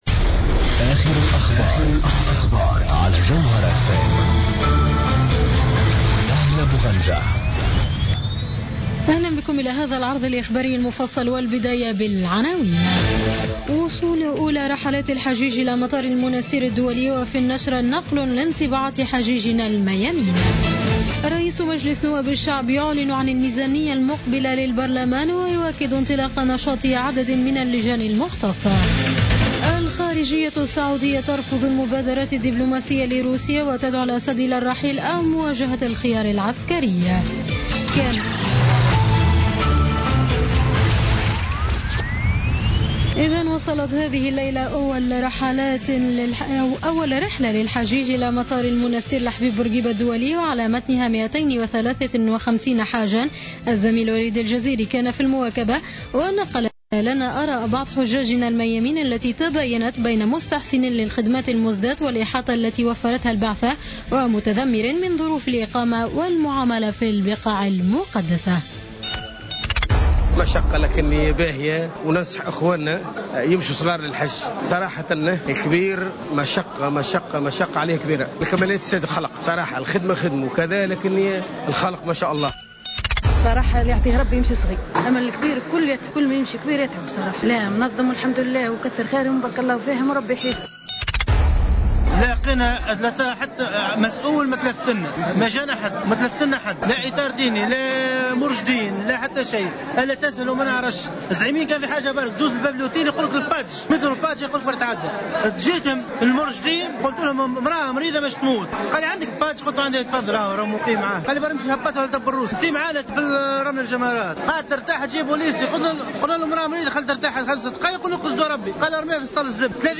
نشرة أخبار منتصف الليل ليوم الاربعاء 30 سبتمبر 2015